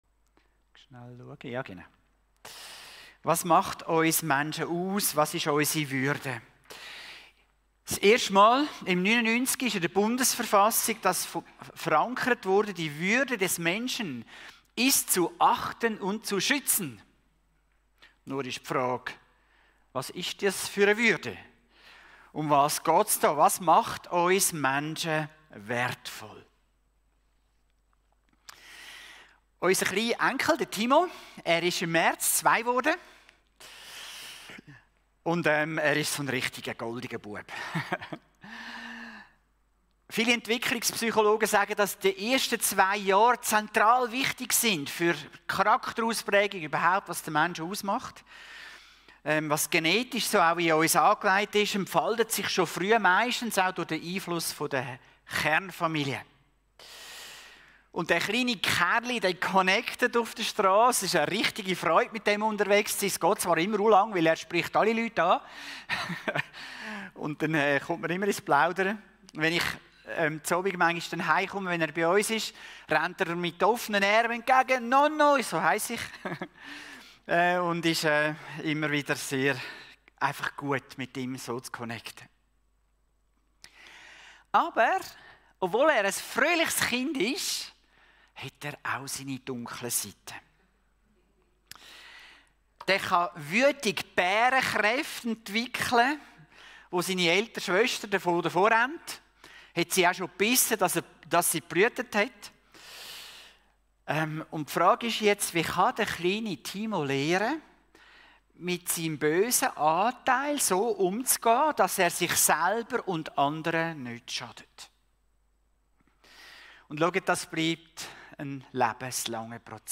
Gottesdienst：-Menschenwuerdig-was-macht-uns-Menschen-aus.mp3